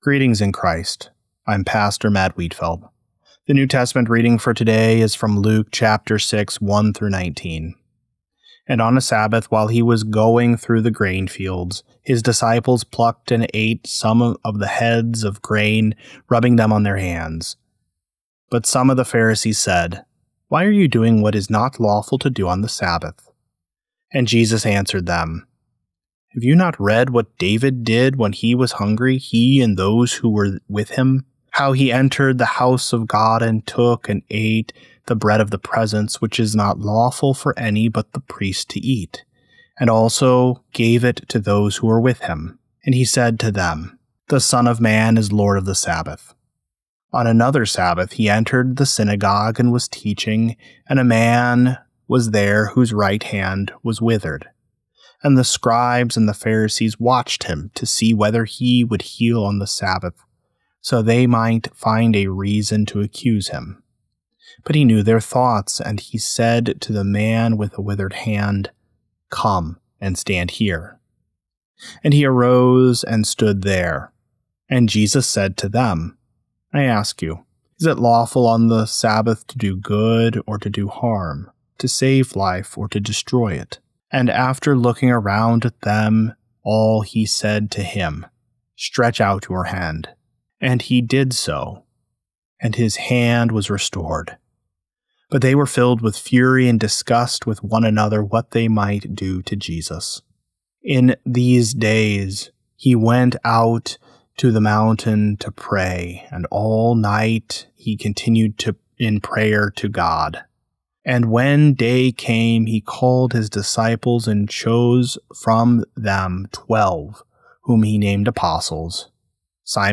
Morning Prayer Sermonette: Luke 6:1-19
Hear a guest pastor give a short sermonette based on the day’s Daily Lectionary New Testament text during Morning and Evening Prayer.